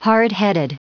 Prononciation du mot hardheaded en anglais (fichier audio)
Prononciation du mot : hardheaded